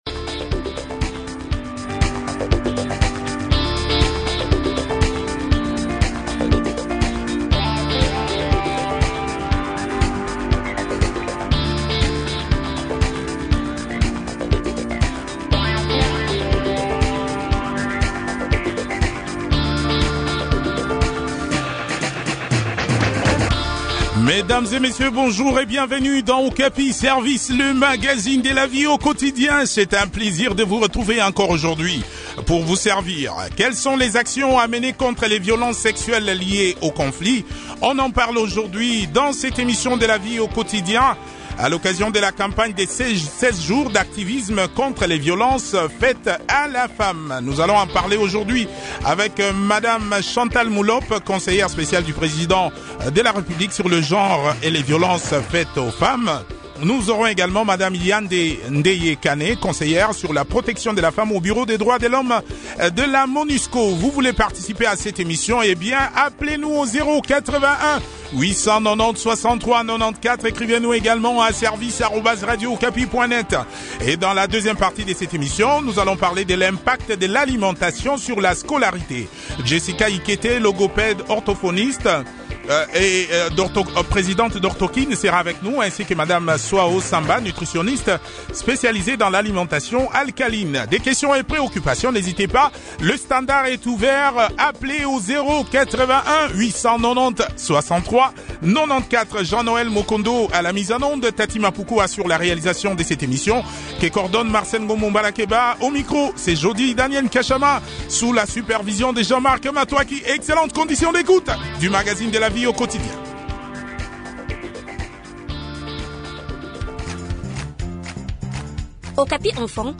Chantal Mulop, conseillère spéciale du président de République en charge du genre et les violences faites aux femmes a aussi participé à cette émission.